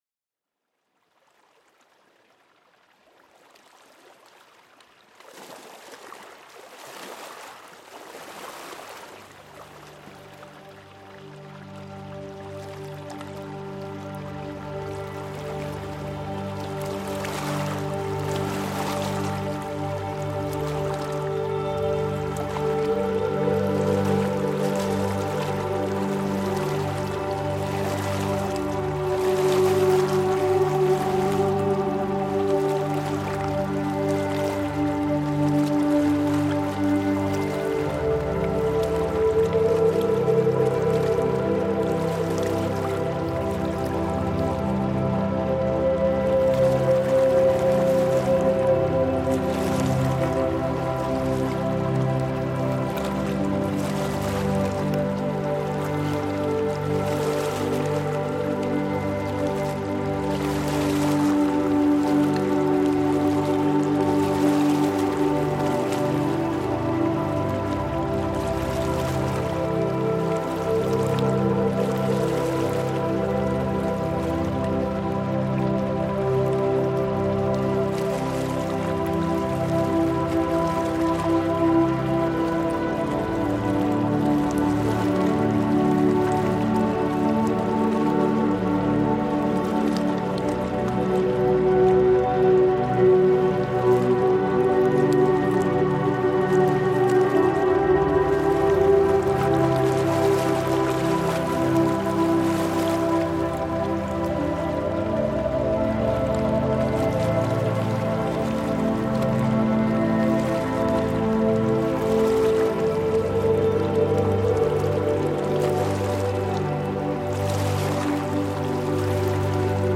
meditation
آهنگ بی کلام